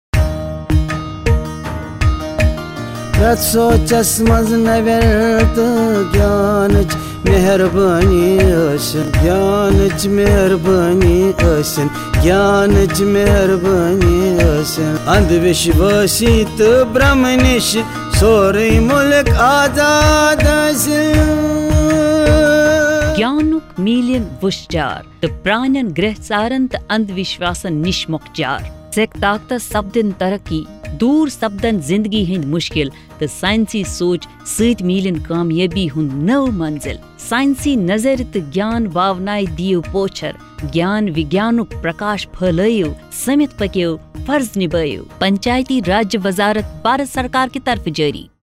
87 Fundamental Duty 8th Fundamental Duty Develop scientific temper Radio Jingle Kashmiri